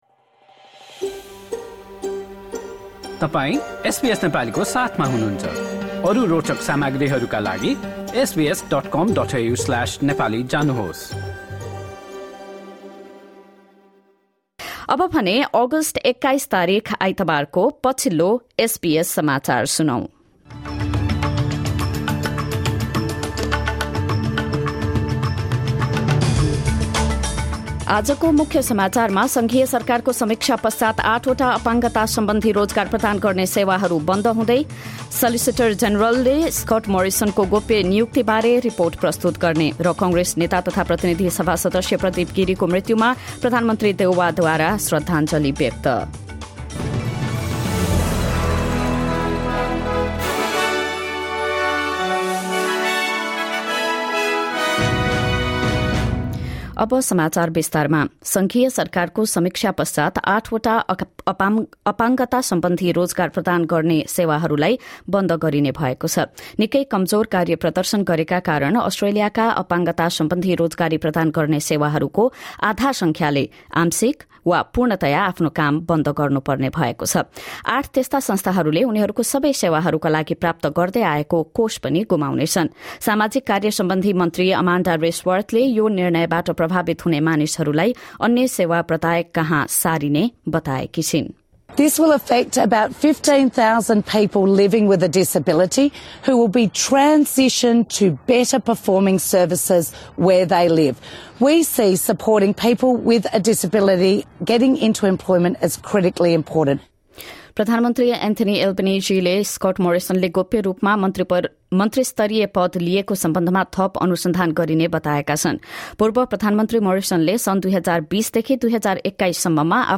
Listen to the latest news headlines from Australia in Nepali. In this bulletin, Eight providers of disability employment services are to be shut down after a federal government review.